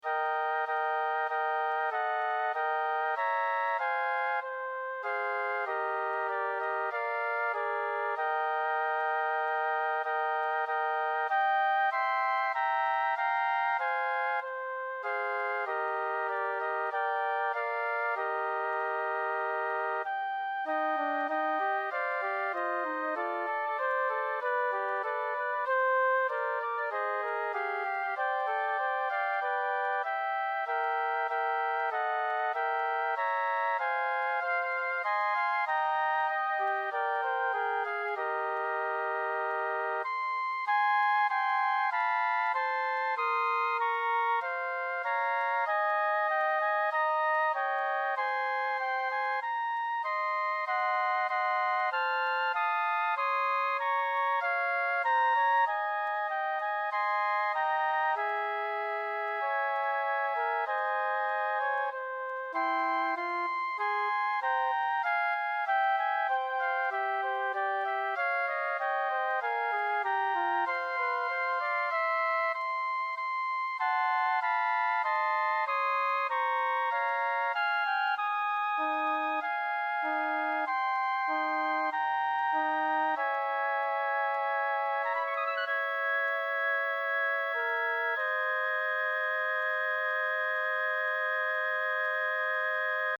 【編成】フルート三重奏（3 Flute）
転調を含め、2コーラス分の長さです。